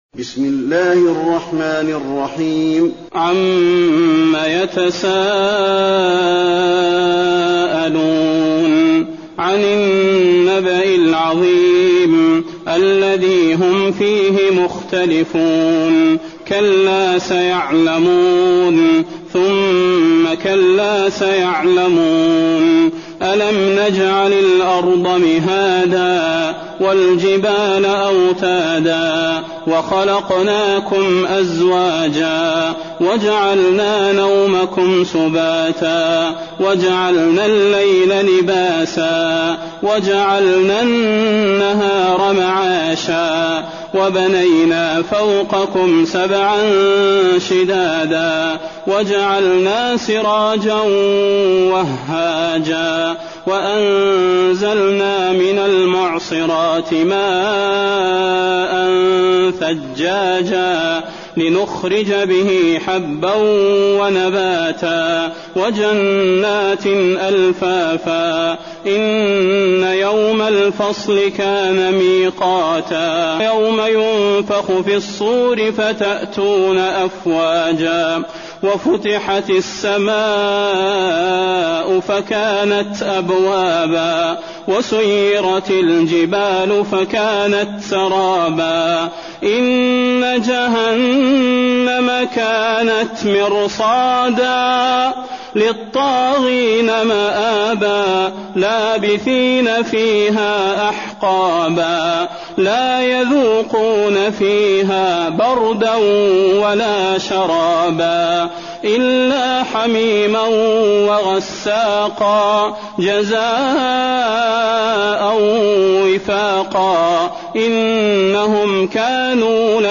المكان: المسجد النبوي النبأ